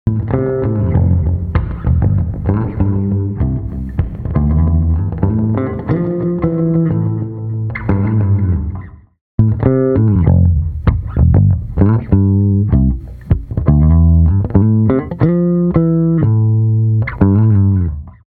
UltraReverb | Bass | Preset: Delayed Verbs
Bass-Delayed-Verbs.mp3